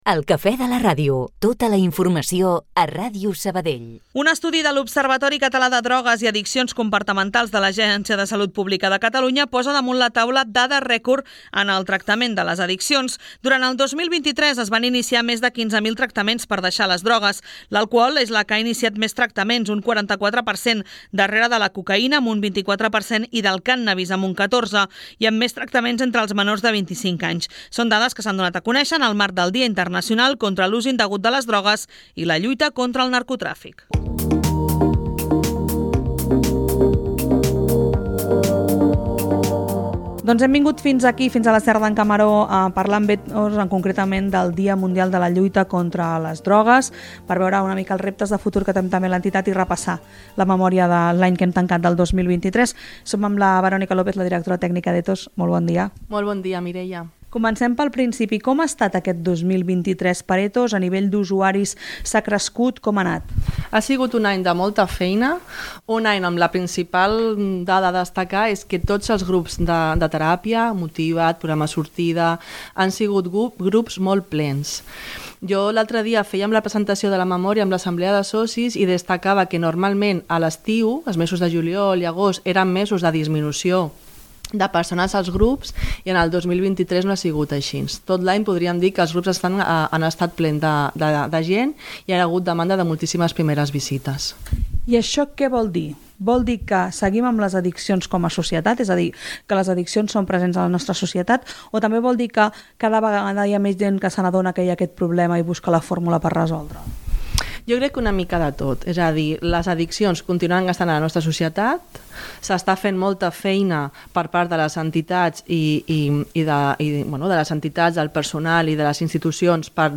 una entrevista